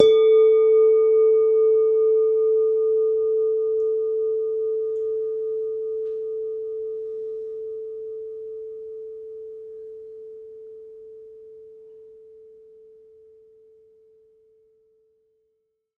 mono_bell_-5_A_16sec
bell bells bell-set bell-tone bong ding dong ping sound effect free sound royalty free Sound Effects